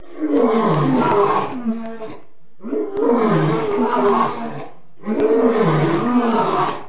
دانلود آهنگ حیوانات جنگلی 105 از افکت صوتی انسان و موجودات زنده
جلوه های صوتی
دانلود صدای حیوانات جنگلی 105 از ساعد نیوز با لینک مستقیم و کیفیت بالا